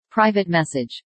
Private_Message.ogg